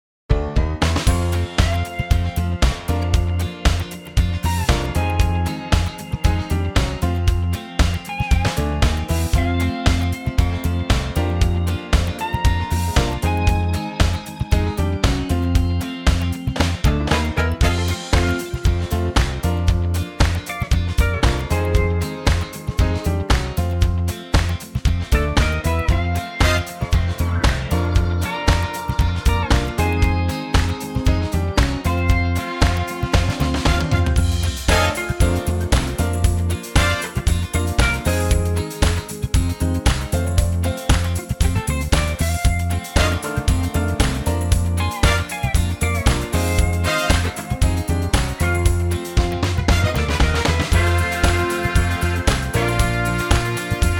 Unique Backing Tracks
key F
key - F - vocal range - Eb to C (blues based licks)